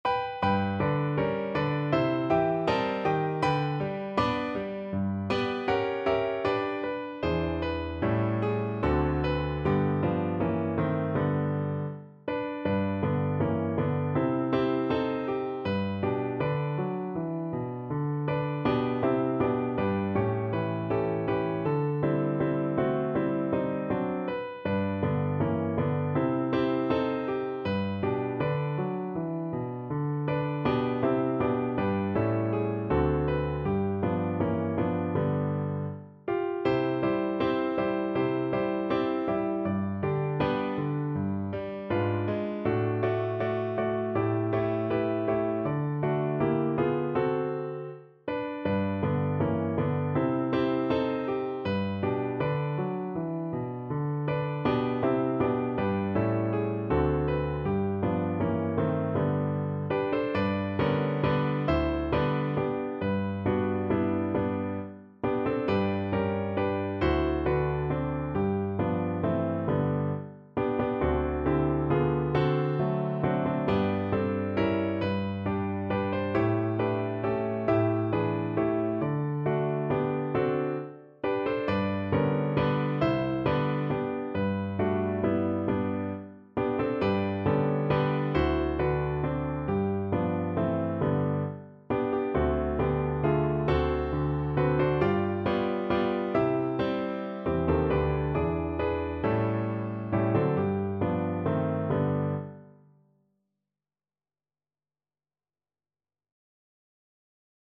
Andante
4/4 (View more 4/4 Music)
Pop (View more Pop Voice Music)